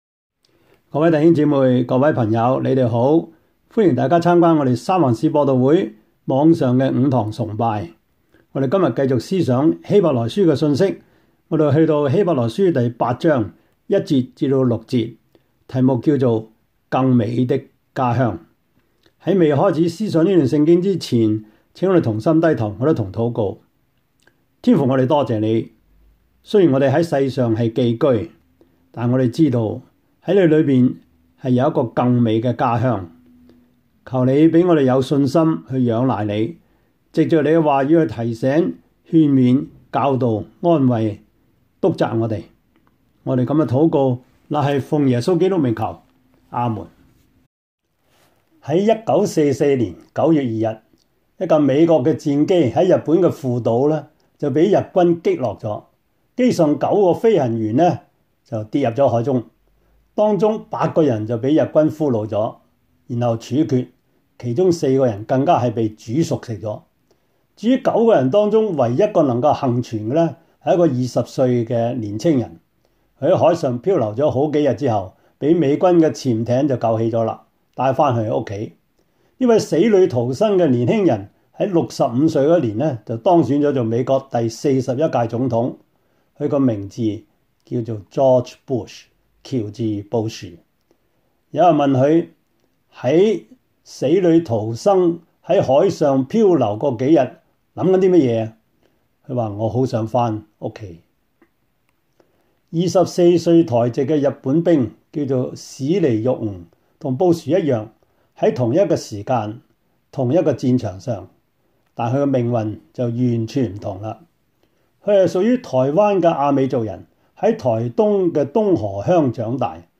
Service Type: 主日崇拜
Topics: 主日證道 « 優先次序 如何讀聖經 – 第八課 »